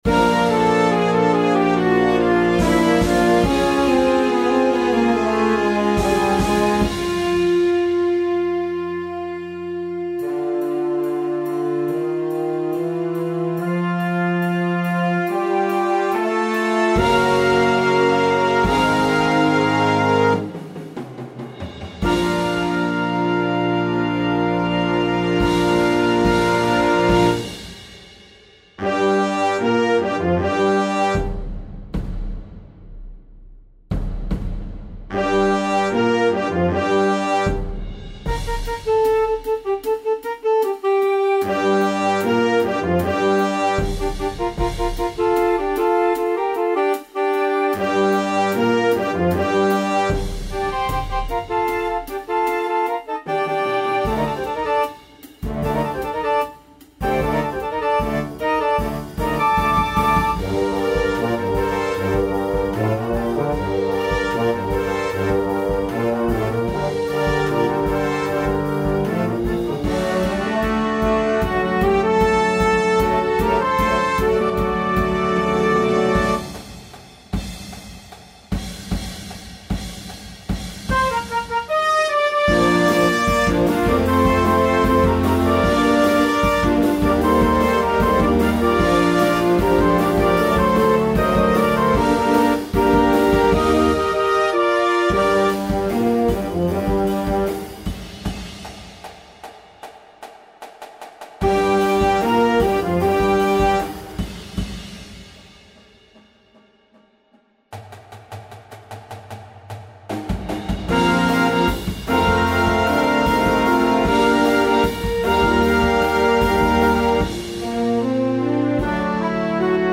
vibrant blend of classic and contemporary music
add cinematic flair
brings a nostalgic, whimsical touch to complete the journey